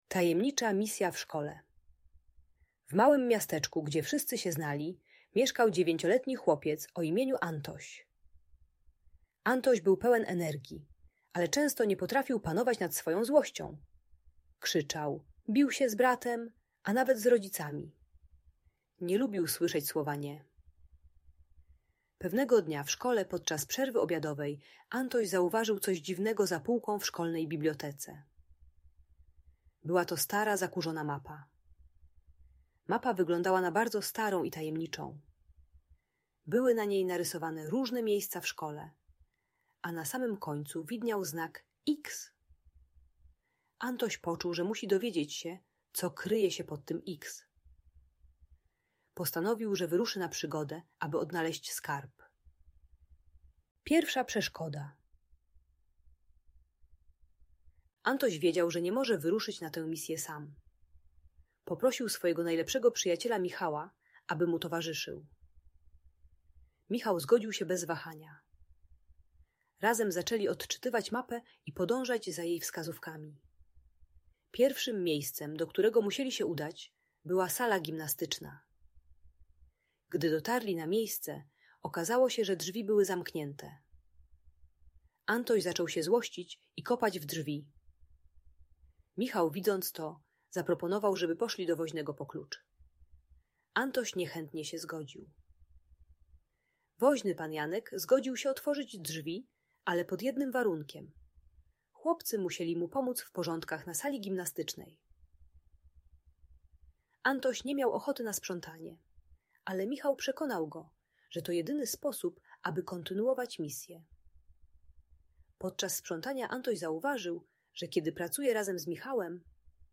Tajemnicza misja w szkole - przygoda Antosia - Audiobajka